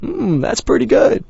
b_umthatsgood.wav